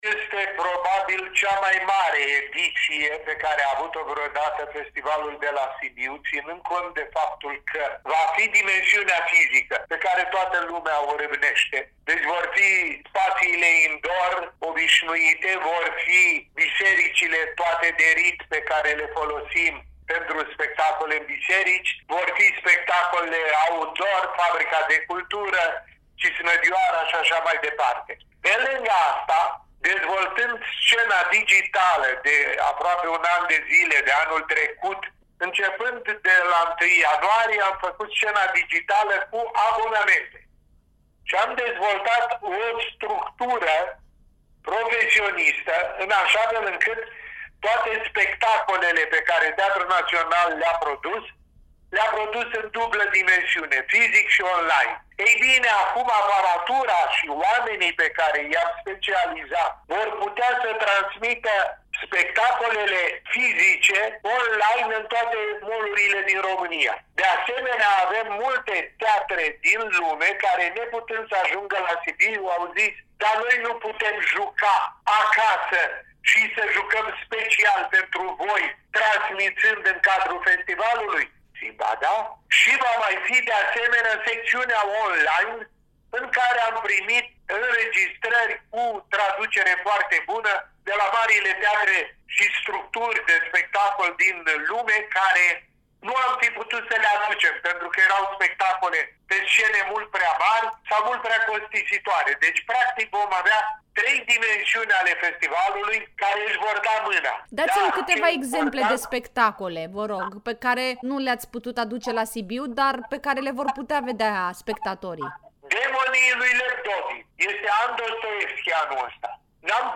interviu-SITE-Constantin-Chiriac-FITS-2021.mp3